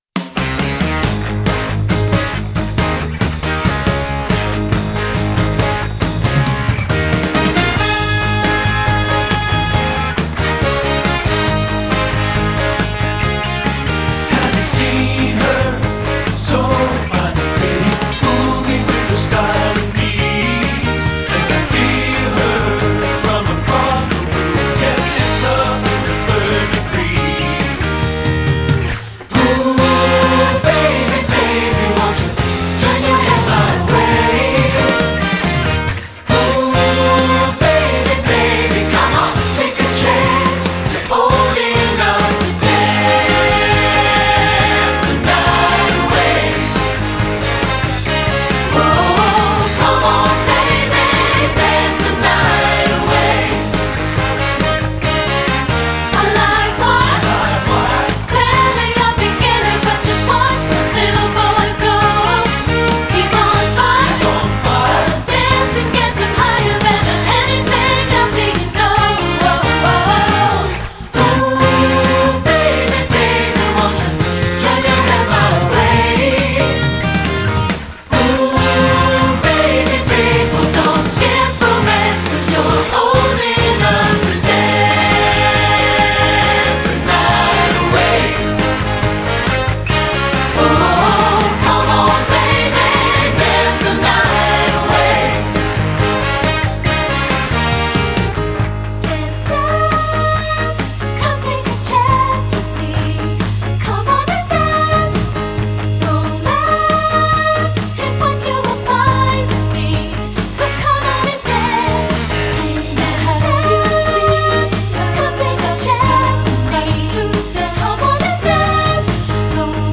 Voicing: TBB and Piano